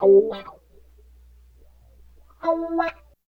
88 GTR 1  -R.wav